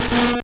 Cri d'Ymphect dans Pokémon Or et Argent.